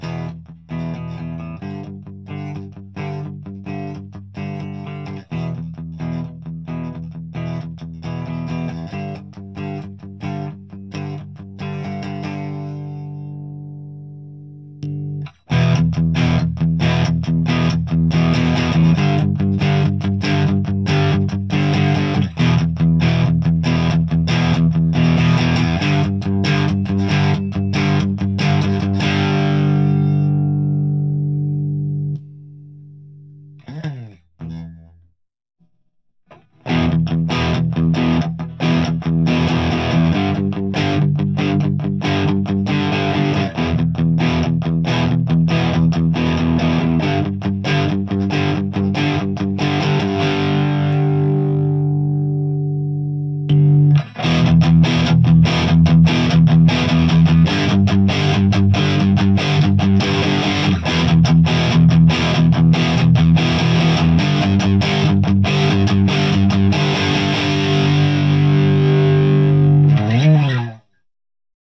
MTRはMRS-8を使いました。マイクはSM57 PG57
CLASSIC GAIN
ゲイン5でクリーンでの比較です。音が太く歪んでいくのが分かります。